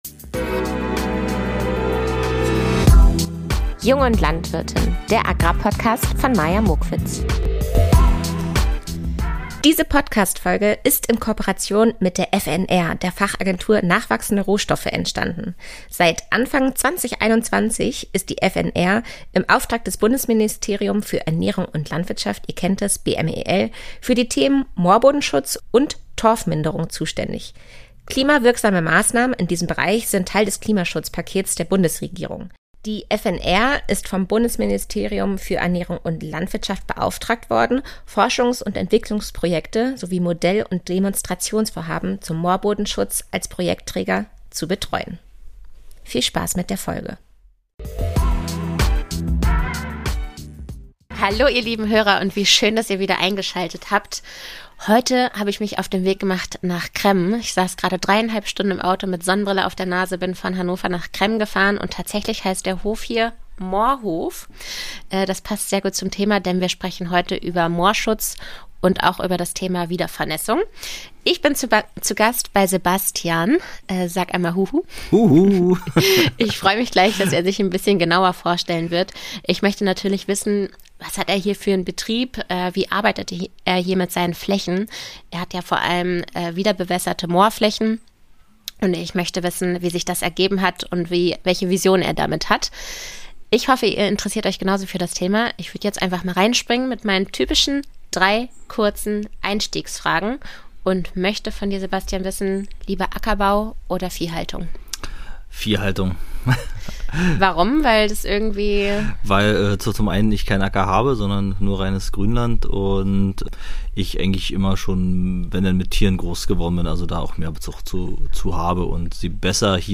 Im Gespräch mit einem Moorlandwirt ~ Jung & Landwirtin Podcast